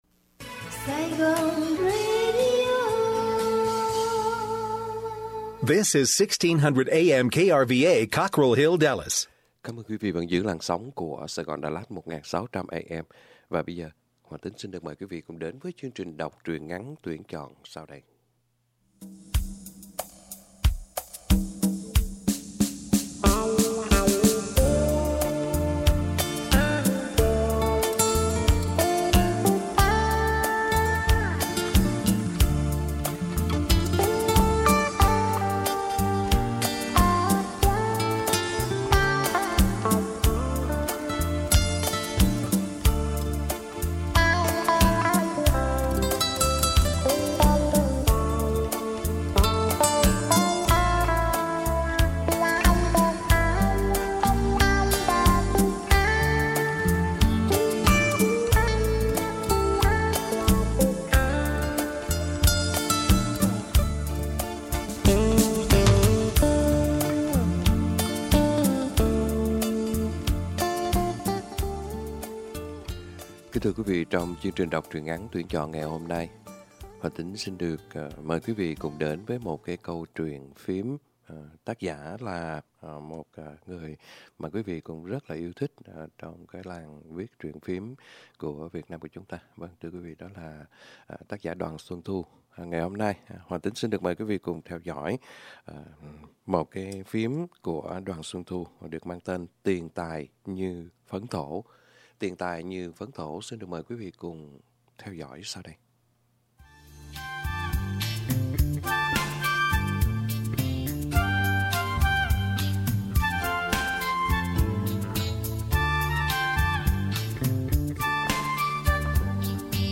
Đọc Truyện = Tiền Tài Như Phấn Thổ !!!